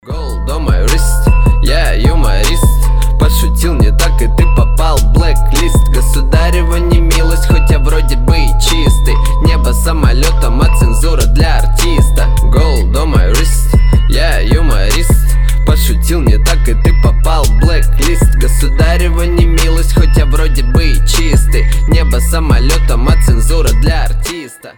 русский рэп
качающие